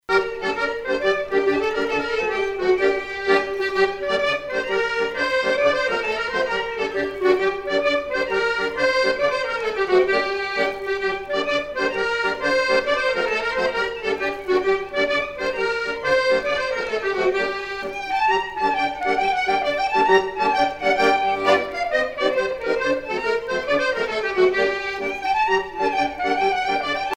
danse : matelote
Pièce musicale éditée